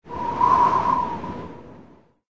SZ_TB_wind_3.ogg